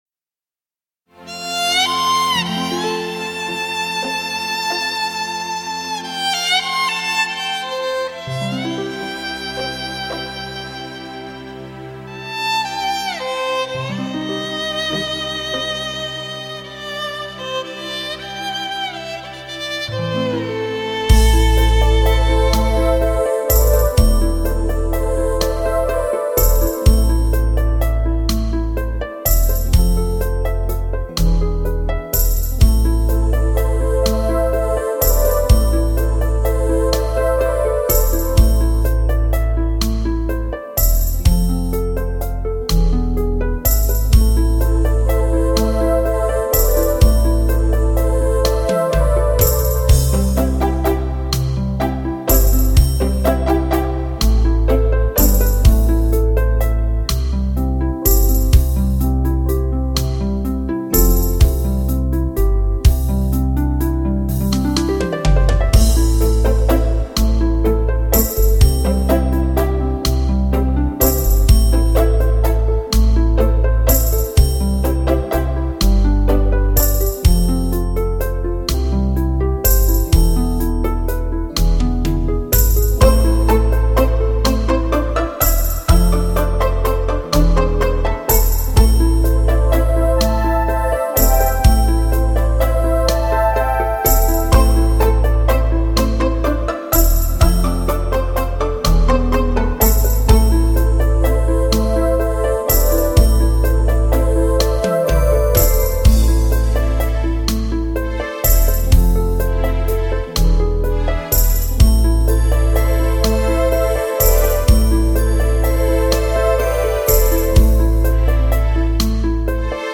无 调式 : C 曲类